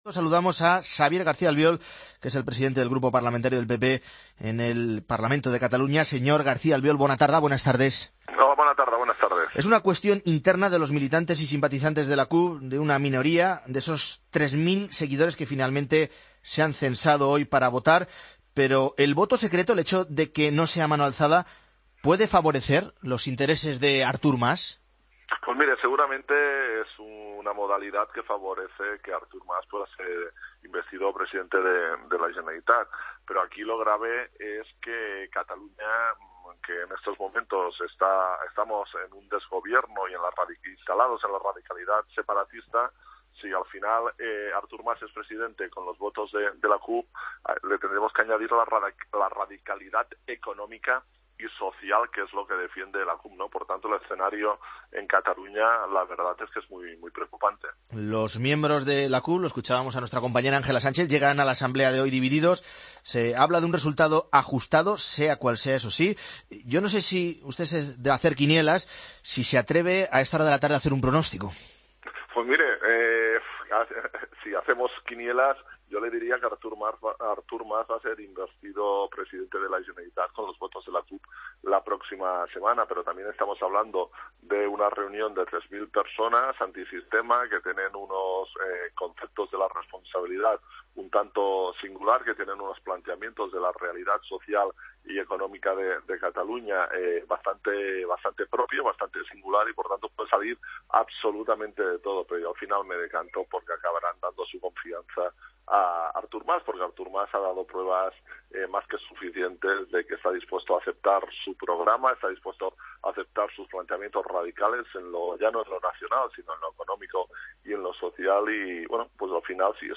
Escucha la entrevista a Xavier García Albiol en Mediodía Cope